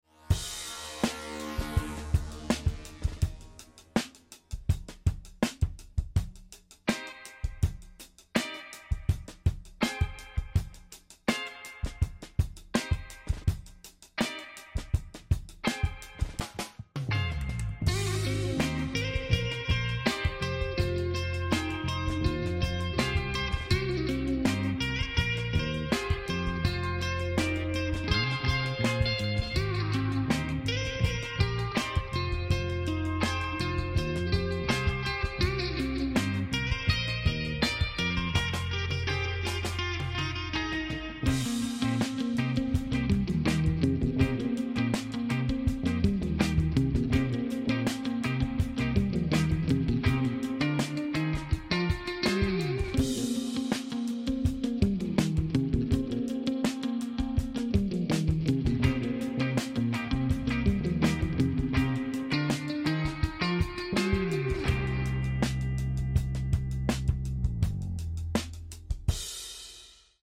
This week he’s replicating the spring reverb and phaser sounds from the song “I Won’t Be There” by Skinshape.